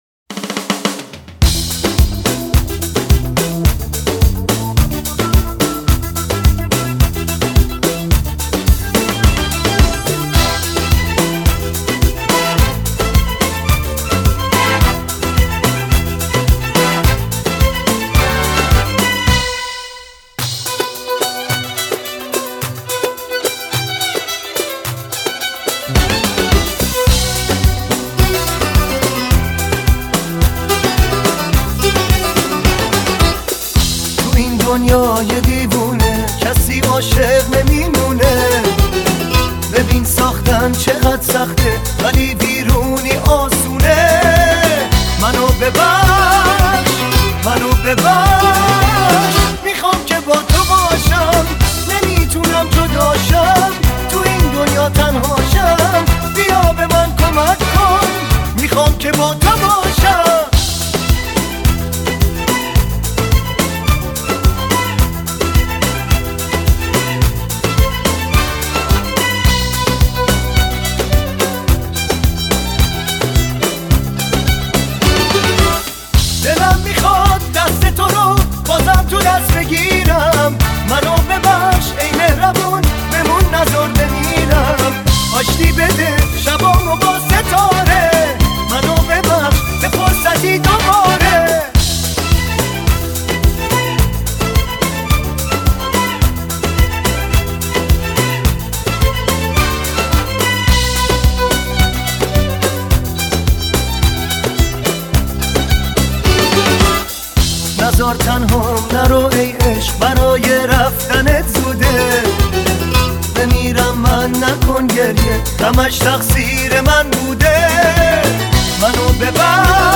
Sing: Pop - آواز: پاپ